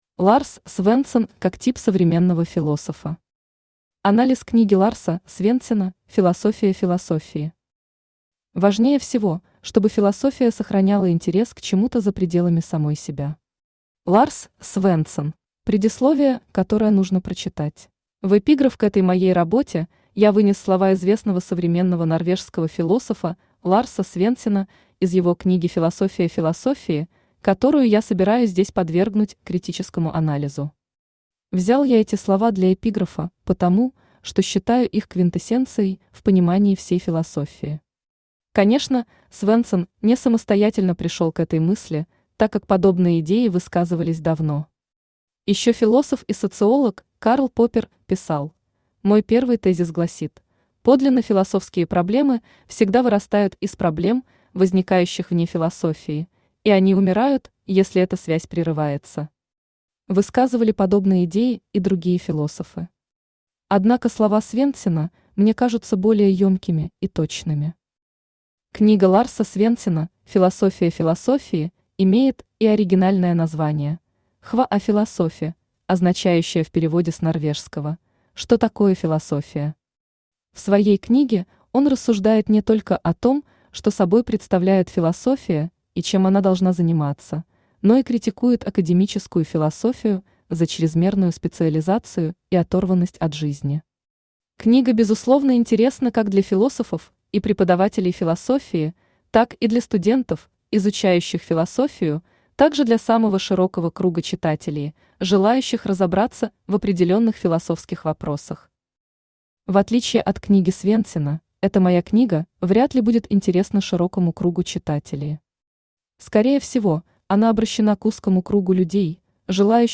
Аудиокнига Ларс Свендсен как тип современного философа | Библиотека аудиокниг
Aудиокнига Ларс Свендсен как тип современного философа Автор Аркадий Арк Читает аудиокнигу Авточтец ЛитРес.